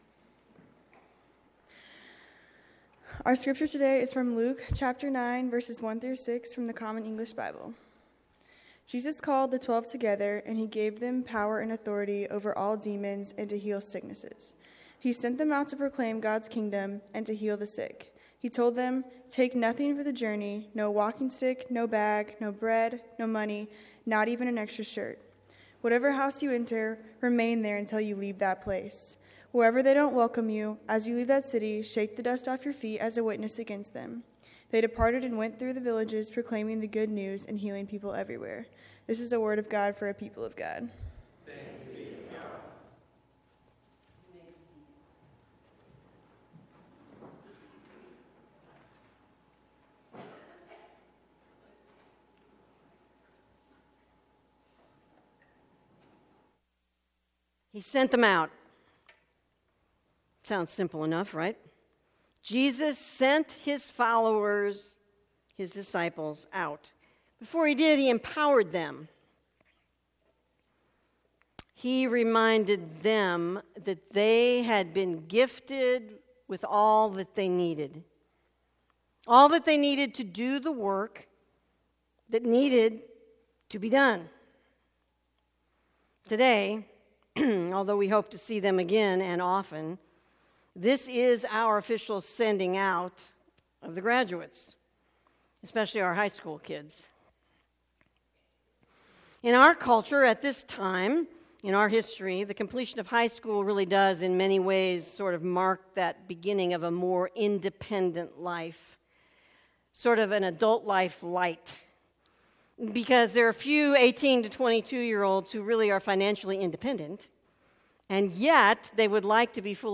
Shawnee Heights United Methodist Church Sermons